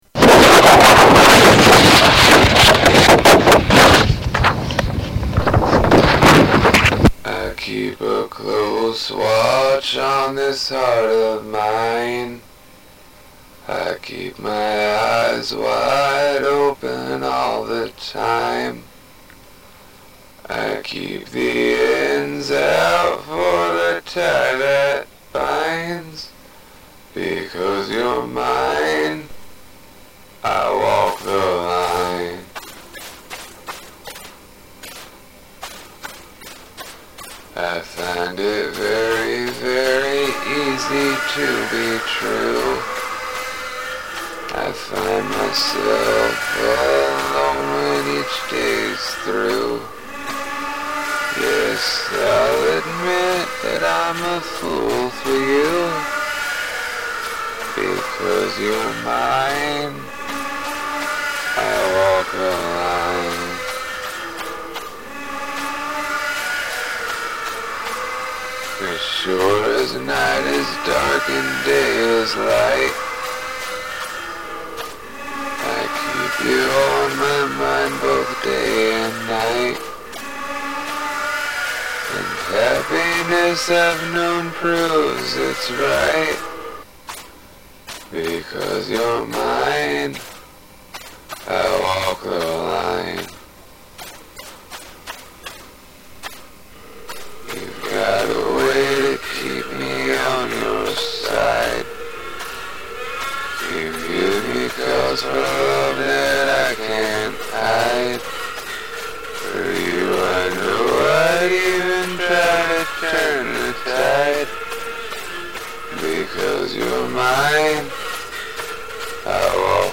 cover
that sounds sort of like someone repairing a motorcycle